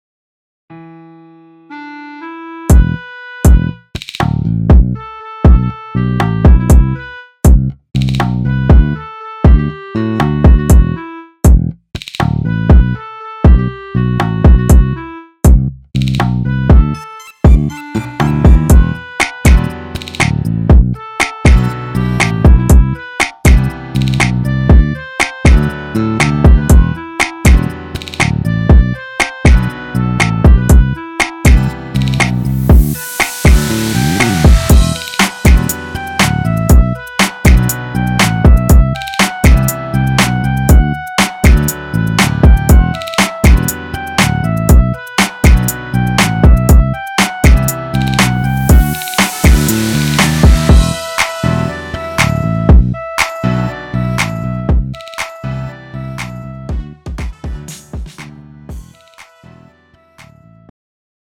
음정 원키 장르 가요